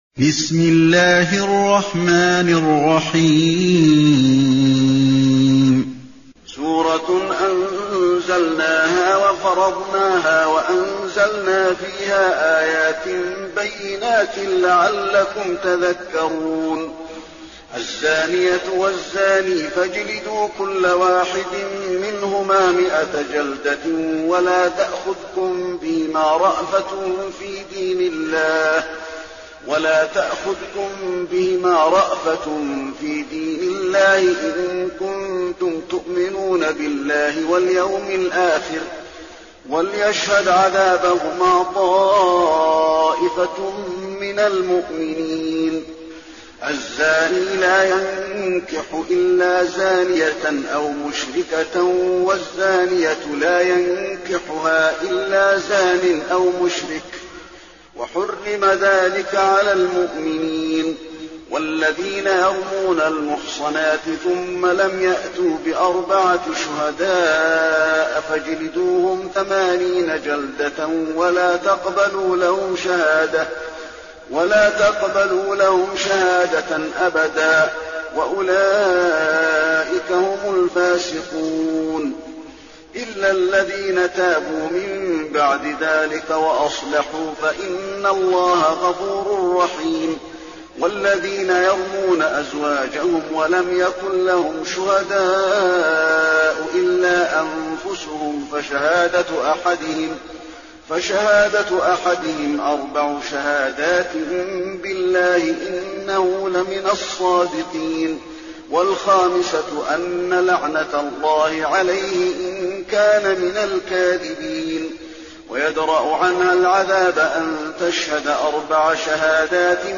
المكان: المسجد النبوي النور